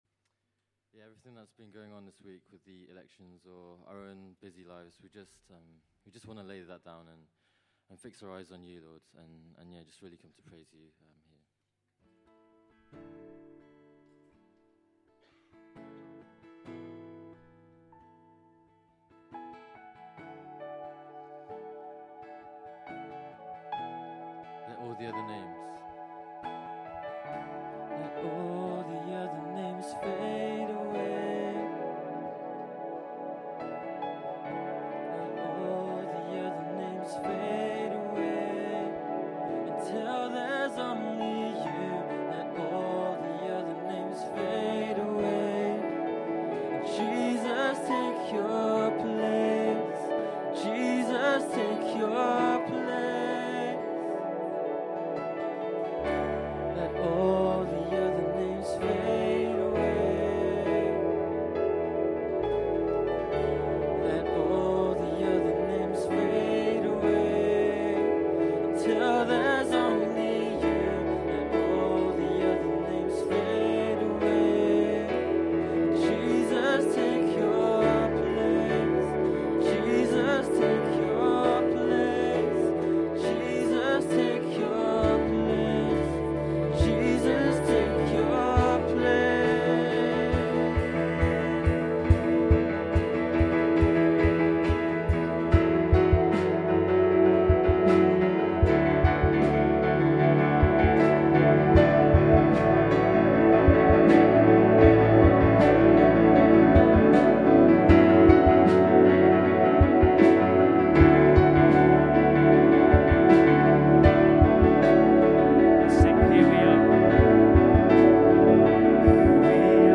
Worship November 13, 2016